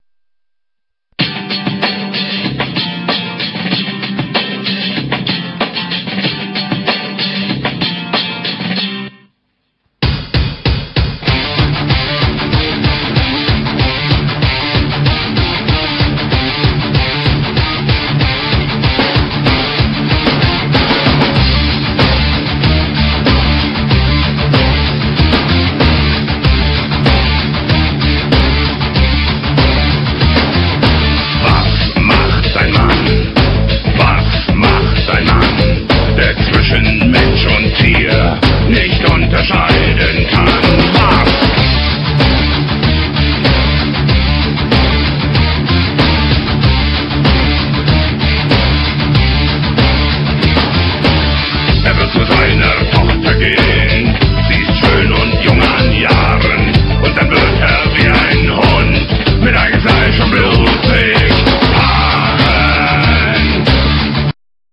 metal
they have a heavy, machine like rythem
and growling vocals.